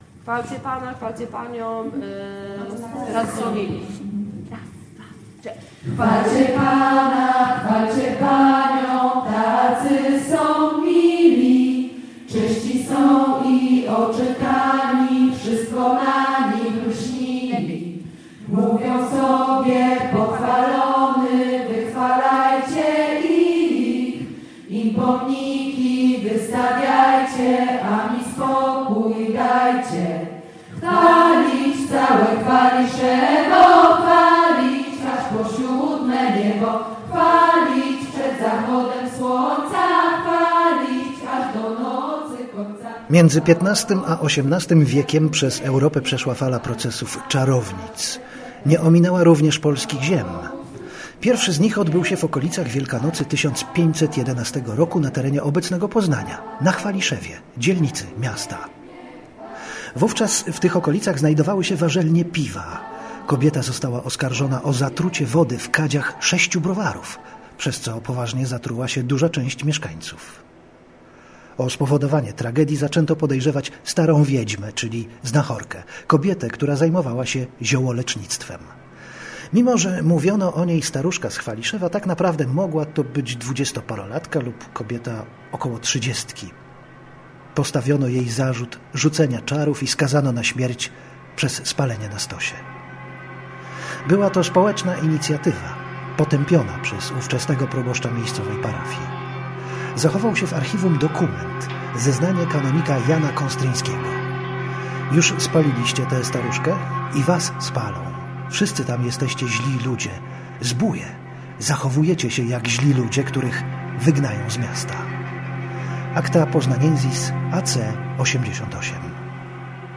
Już płonę, a jeszcze - reportaż